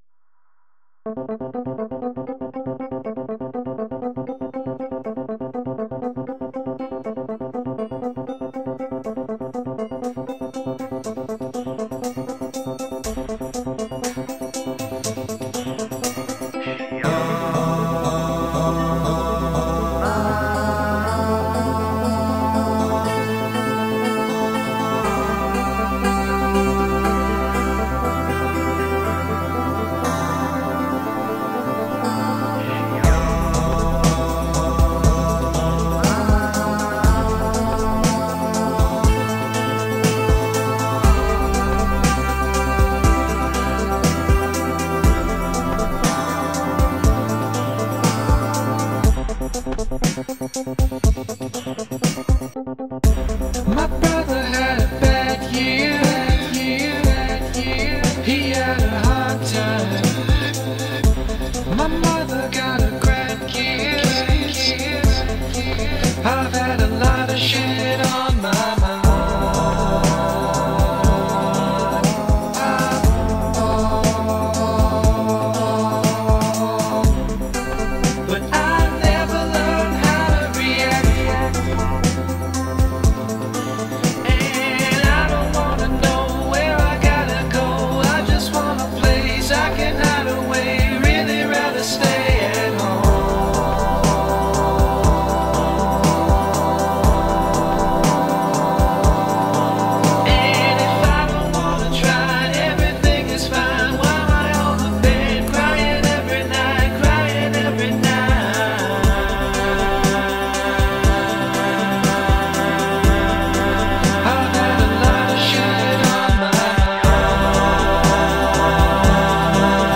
BPM120-120
Audio QualityCut From Video